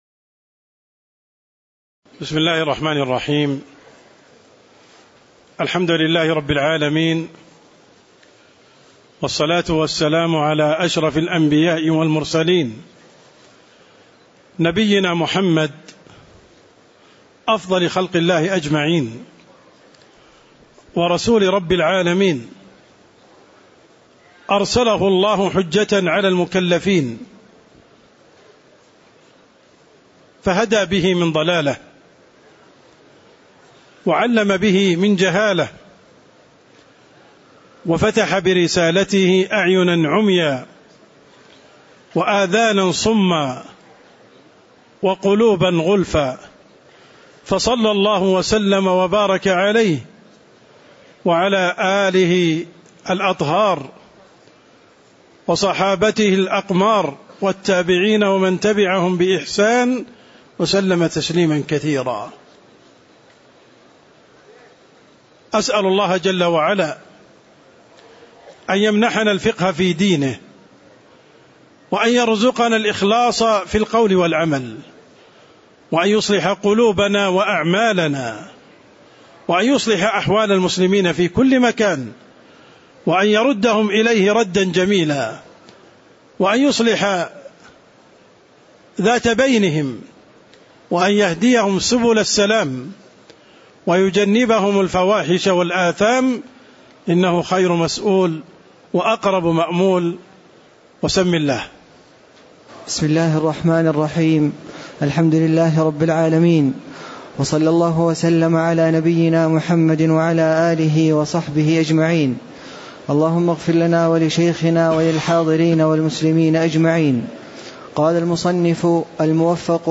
تاريخ النشر ٢٧ ذو القعدة ١٤٣٧ هـ المكان: المسجد النبوي الشيخ: عبدالرحمن السند عبدالرحمن السند المقدمة كتاب الجنائز (01)